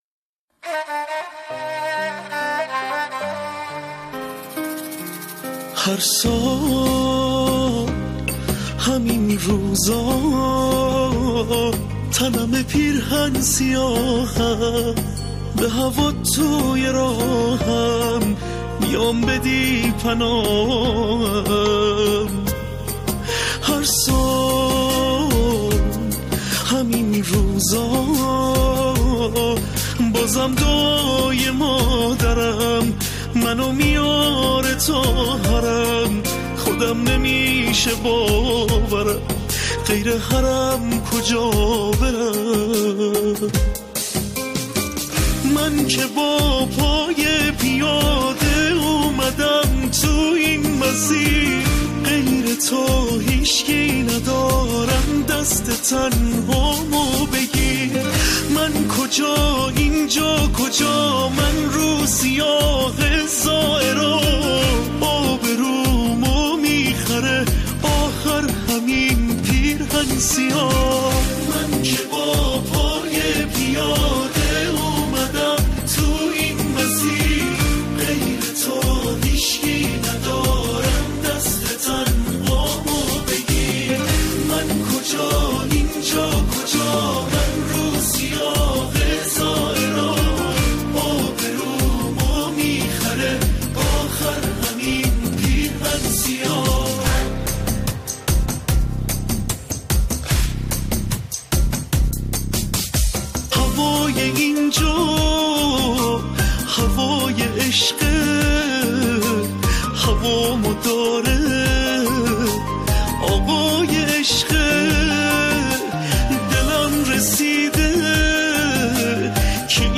این قطعه را می‌توان نمونه‌ای از سرود با یک خواننده دانست.